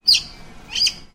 Визг одинокой выдры